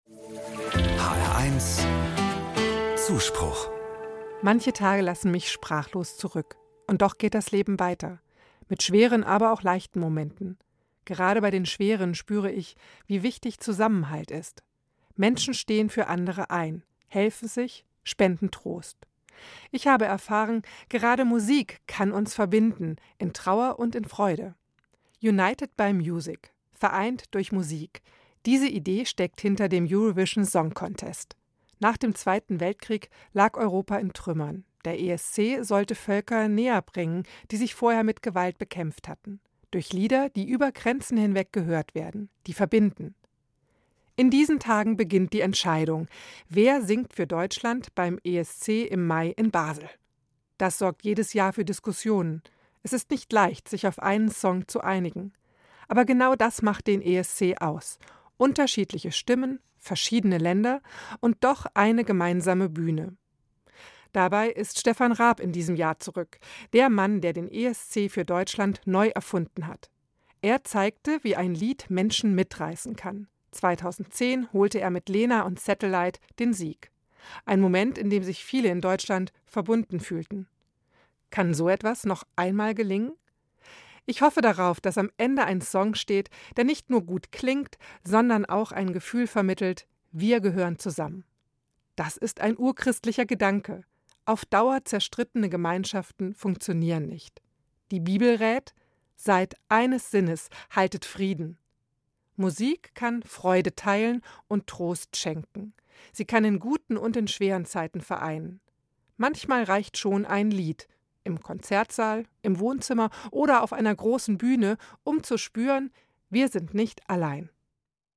Anmoderation: Der Anschlag in München vor zwei Tagen (13.2.25) hat viele erschüttert.